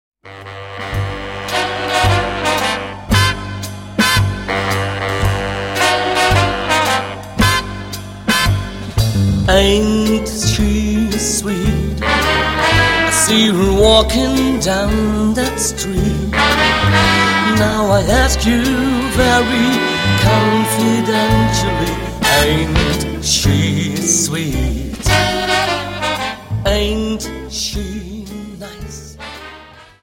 Dance: Slowfox 28s Song